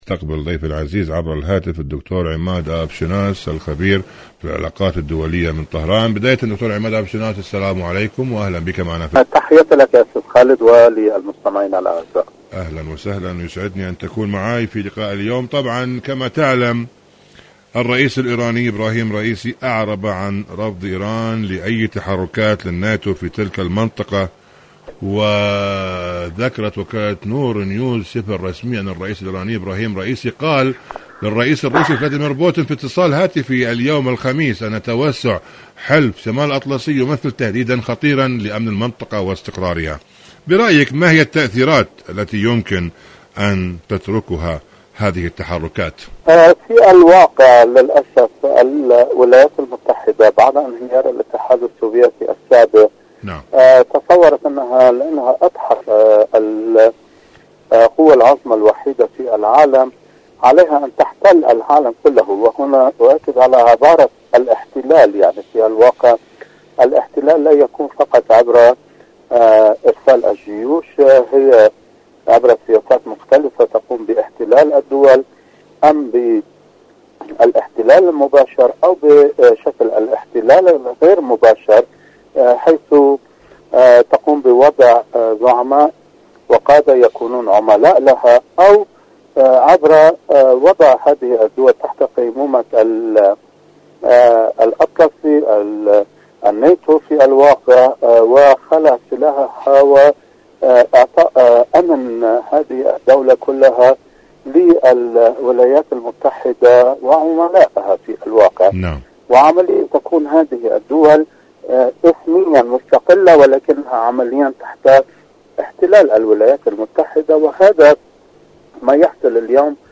مقابلة إذاعية
مقابلات برامج إذاعة طهران العربية برنامج ايران اليوم المشهد السياسي ايران مقابلات إذاعية أوكرانيا الحرب في أوكرانيا روسيا الموقف الإيراني شاركوا هذا الخبر مع أصدقائكم ذات صلة مسيرات يوم القدس العالمي، الرسائل والتداعيات..